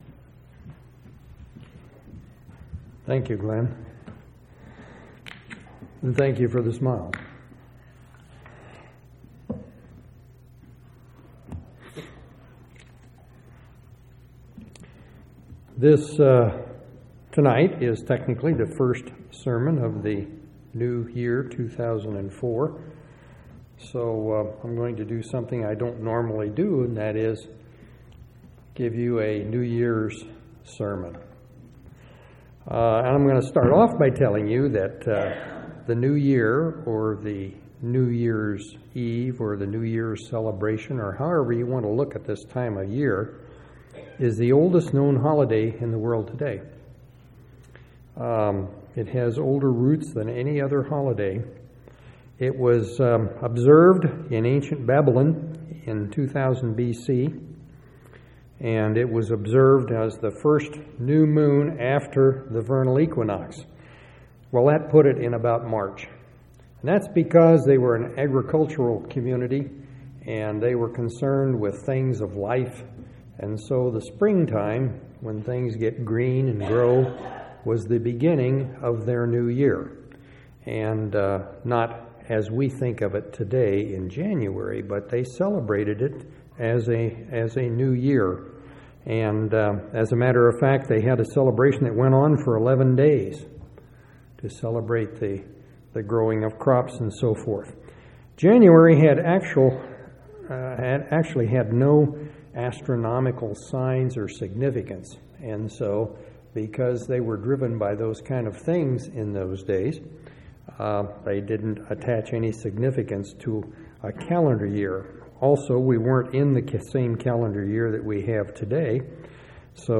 1/4/2004 Location: Temple Lot Local Event